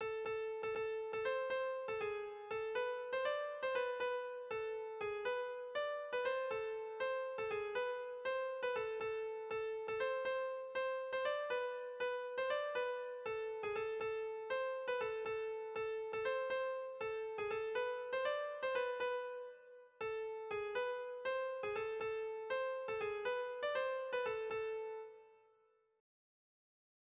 Bertso melodies - View details   To know more about this section
Erlijiozkoa
A-B-C-A-B